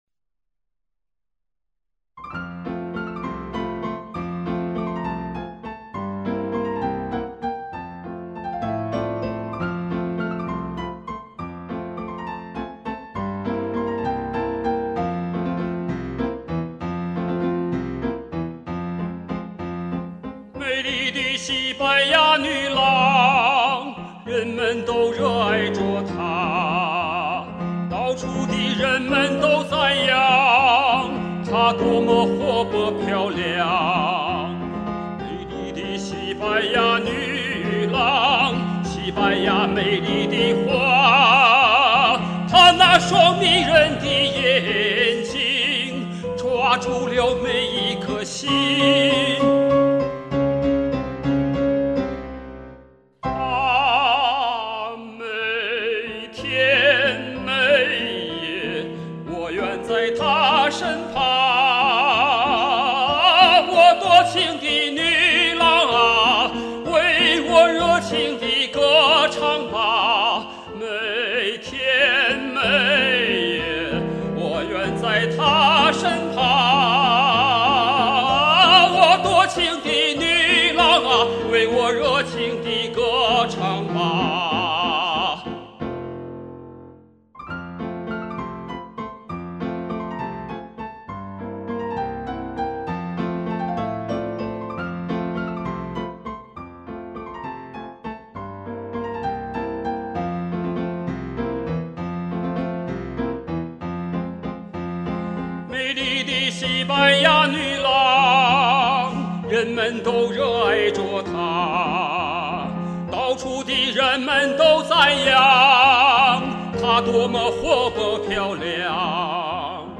當時老師給俺的定位是抒情男高音，主要的不足是唱高音時喉頭緊，腹部支撐力量不夠。
覺得高音處聲音沒有收攏，比較散？